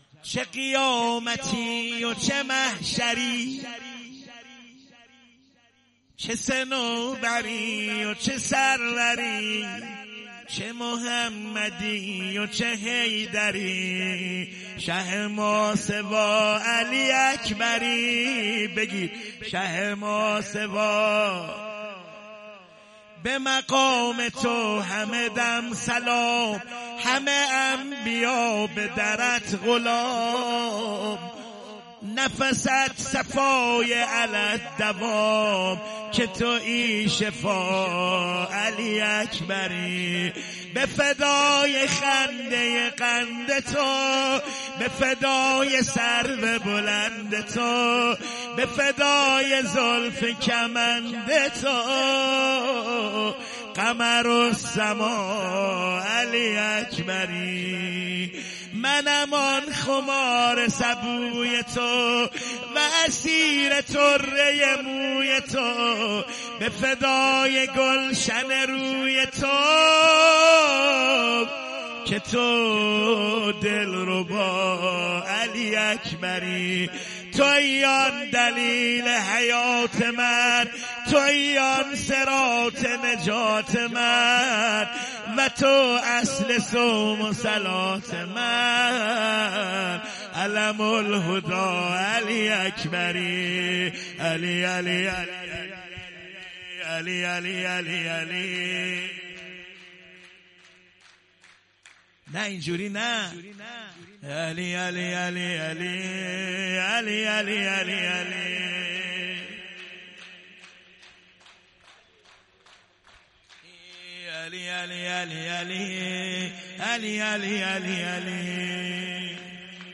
مولودی
مدیحه سرایی